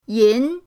yin2.mp3